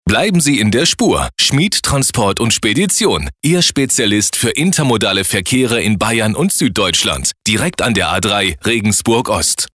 Im August 2022 ging unser neuer Radio-Spot in der Region auf Sendung - zu hören war der Spot am Sender "charivari Regensburg"